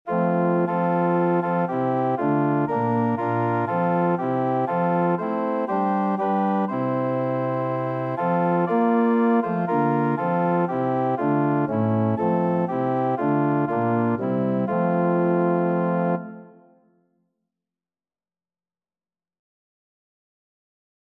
Christmas Christmas Organ Sheet Music While Shepherds Watched Their Flocks by Night (Winchester Old)
Free Sheet music for Organ
F major (Sounding Pitch) (View more F major Music for Organ )
4/4 (View more 4/4 Music)
Organ  (View more Easy Organ Music)
Traditional (View more Traditional Organ Music)